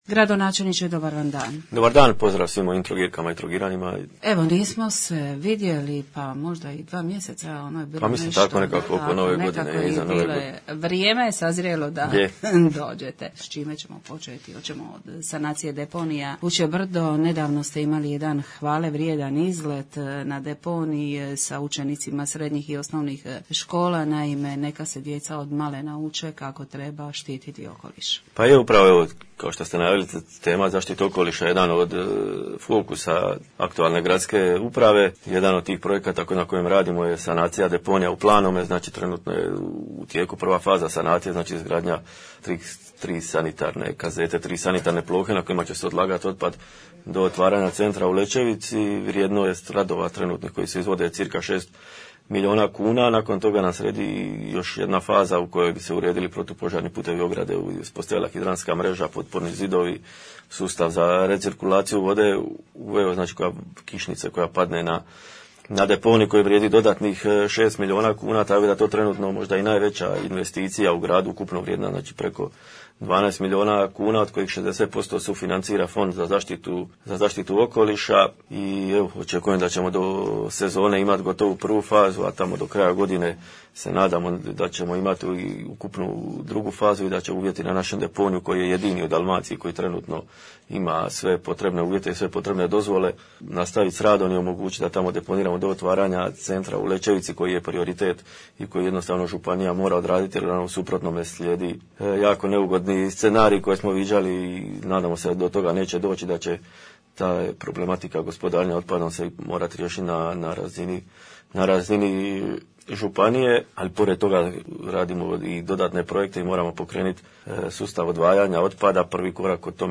Gradonačelnik gost radijske emisije “Dobar dan”